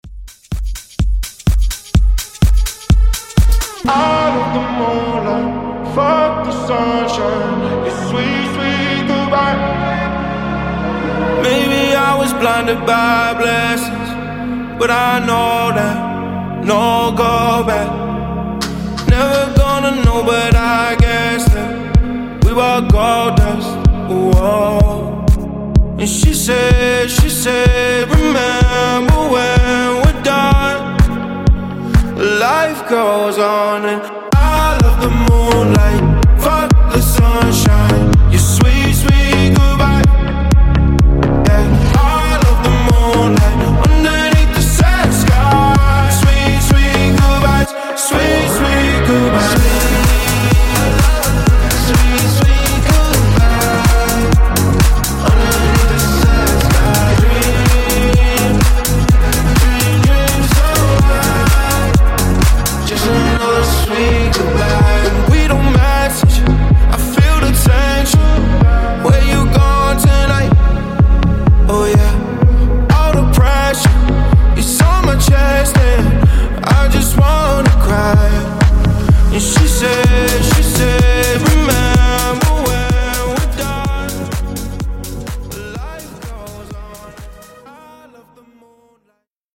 Genres: RE-DRUM , TOP40
Clean BPM: 144 Time